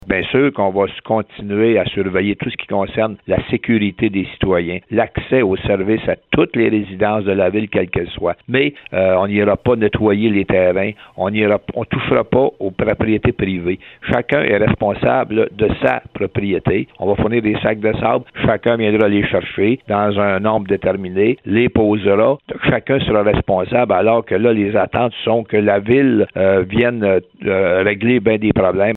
Comme l’a expliqué le maire de Bécancour, Jean-Guy Dubois, ce matin dans l’émission Debout c’est l’heure au VIA 90,5 FM, elle souhaite également mettre sur pied un protocole sur ce qu’elle fera et ne fera pas lors des prochaines inondations printanières.